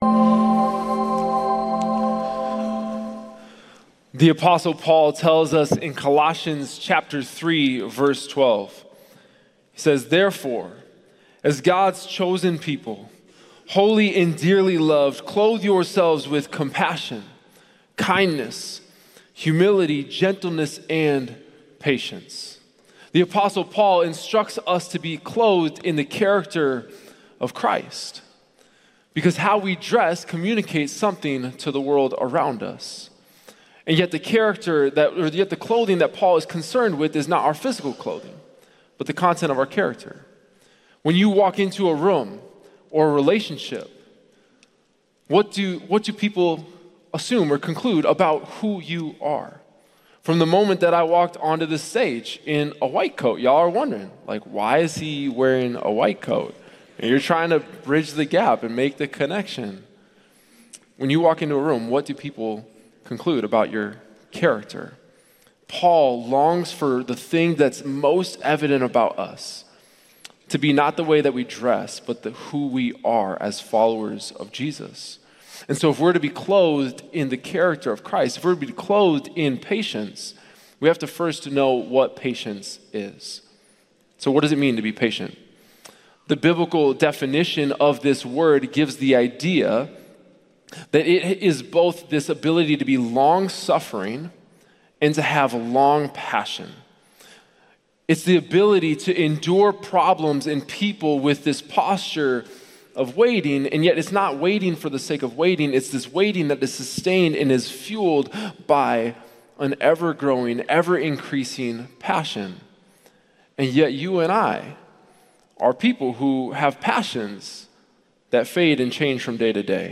Share this Sermon Facebook Twitter Previous Gentleness Next Forgiveness More from Series October 12